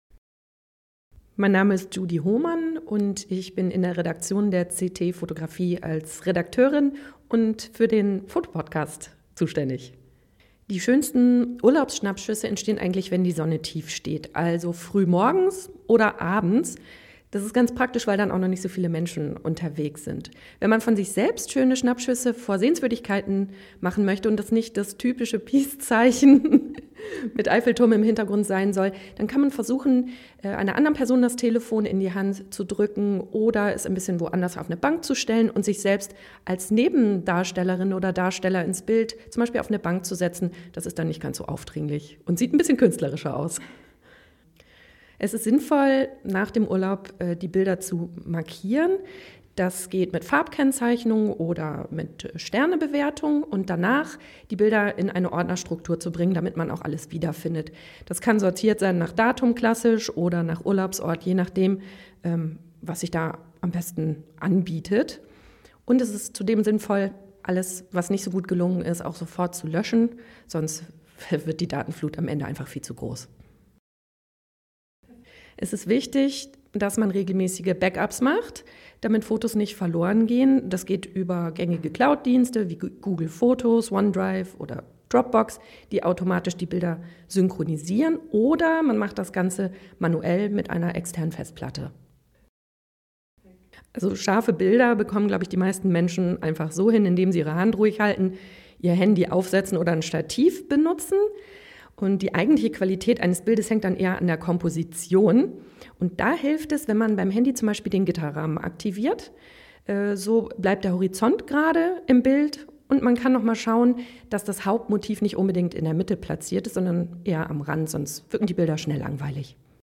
O-Ton: Weltfototag – Quelle: Heise Gruppe
O-Ton_Weltfototag.mp3